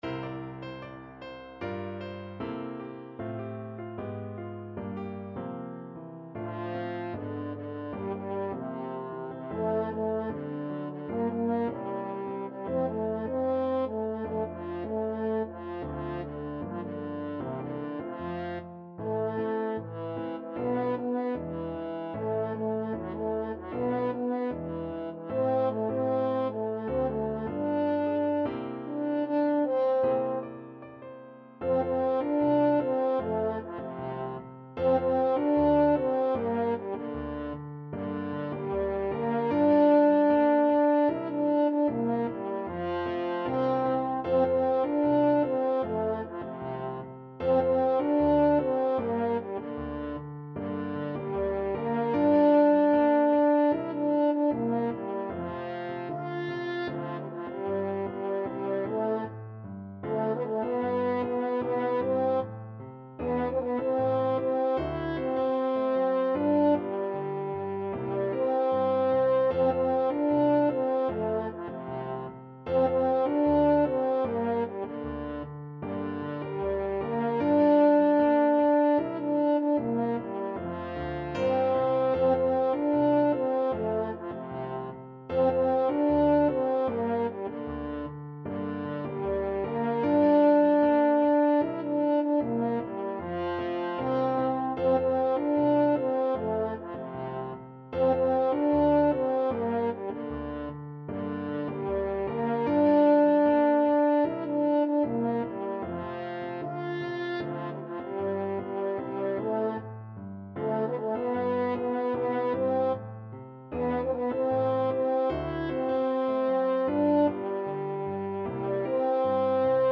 2/2 (View more 2/2 Music)
Pop (View more Pop French Horn Music)